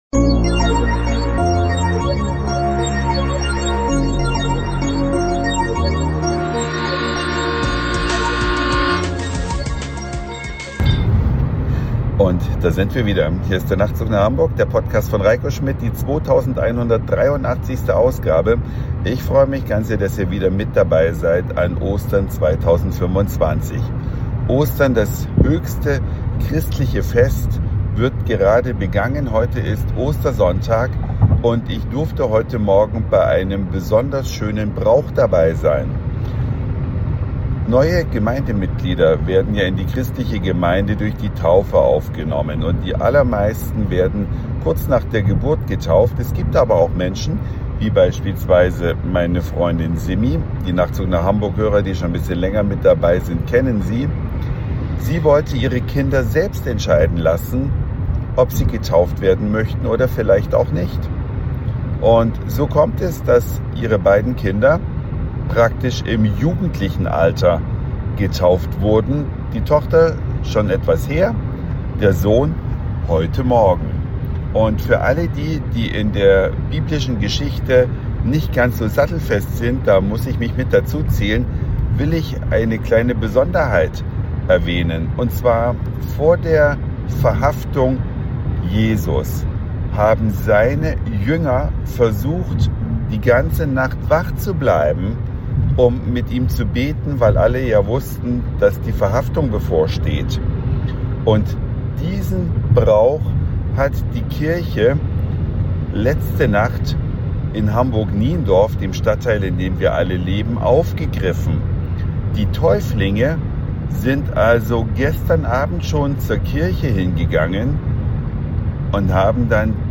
Ostersonntag, 5:30 Uhr, Verheißungskirche Niendorf